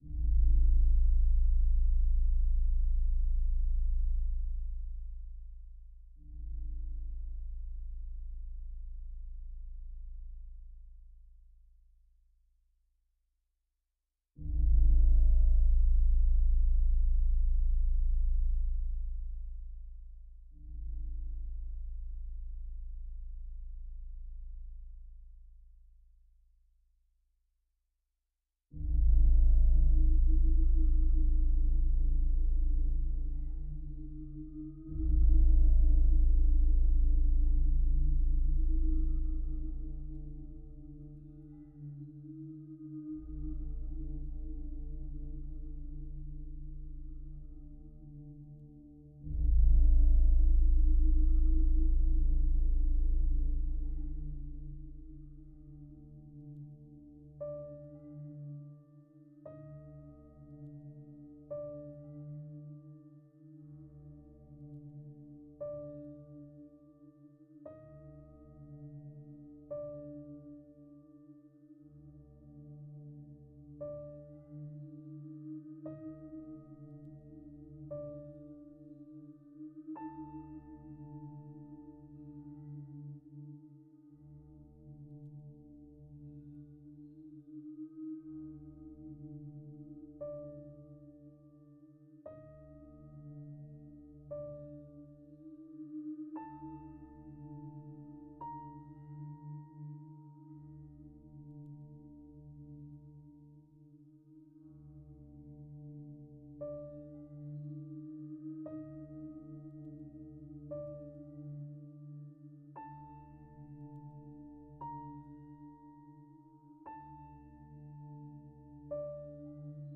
flute, sax, electronics